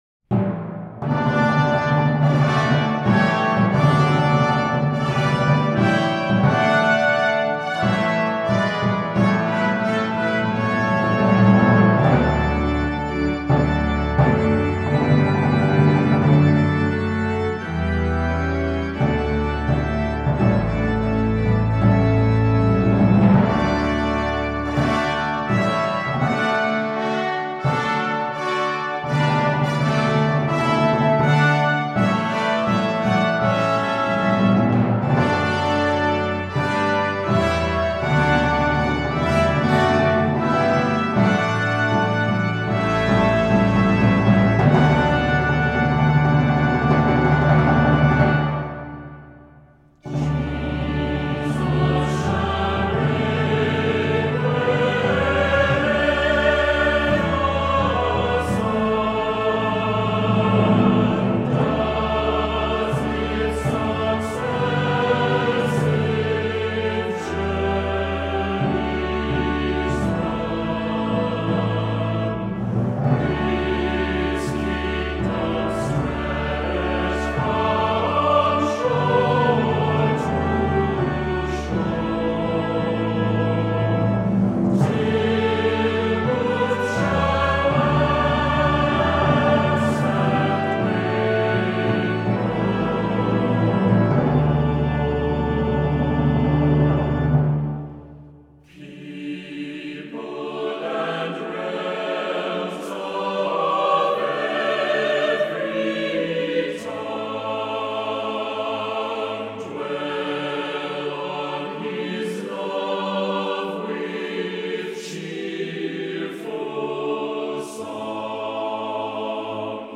Voicing: SATB; Descant; Assembly